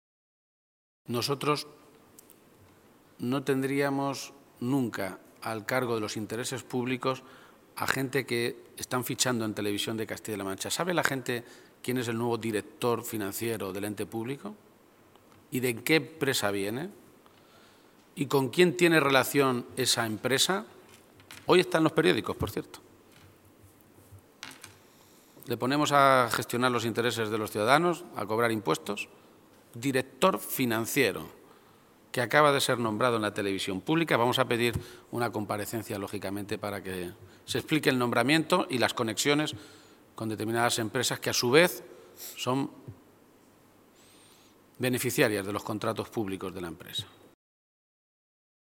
García-Page se pronunciaba de esta manera esta mañana, en Toledo, a preguntas de los medios de comunicación, y añadía que “ahora resulta que cuando el Gobierno de Cospedal llama a la UGT para firmar un acuerdo de salud laboral, la UGT es buena. Pero si la UGT recurre el Plan de Recursos Humanos de Cospedal para el futuro Hospital de Toledo, es Page el que está detrás”.
Cortes de audio de la rueda de prensa